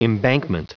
Prononciation du mot embankment en anglais (fichier audio)
Prononciation du mot : embankment